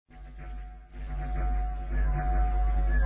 Специально для вас (для собаки повысить на две октавы).
Это похоже на некие горловые звуки.